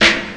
• 00's Crisp Hip-Hop Snare Drum Sample F Key 19.wav
Royality free steel snare drum sample tuned to the F note. Loudest frequency: 2391Hz
00s-crisp-hip-hop-snare-drum-sample-f-key-19-wxT.wav